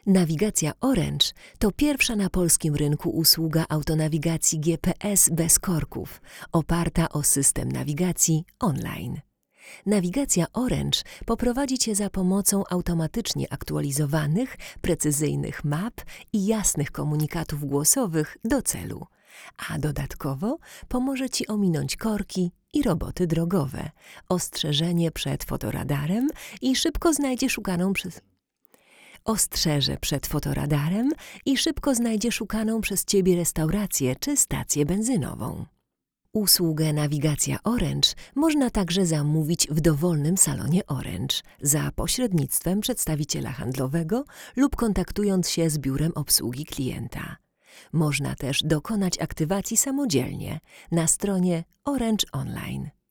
Neumann TLM-103 + UAudio 710